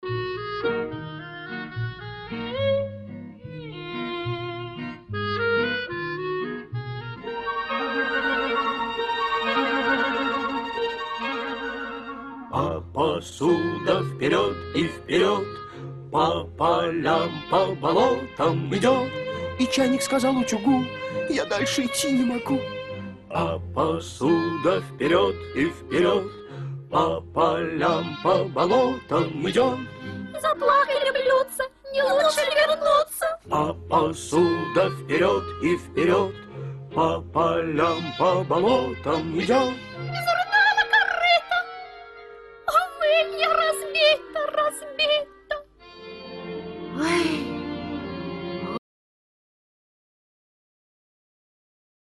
Вспомнилась детская песенка.